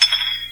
bottle.ogg